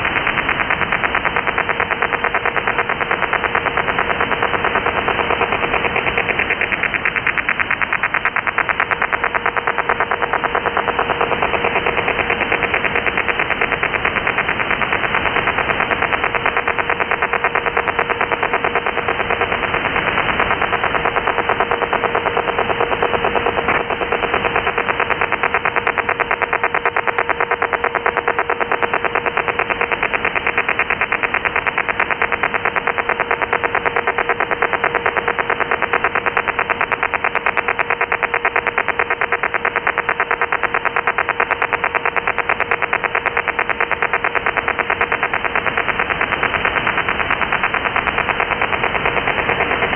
Неизвестная передача.
Начало » Записи » Радиоcигналы на опознание и анализ